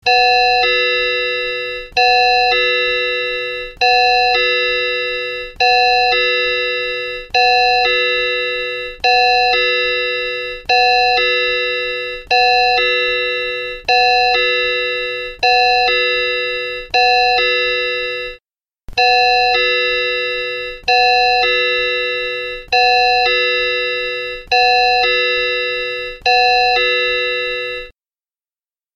Nhạc chuông báo thức Audio size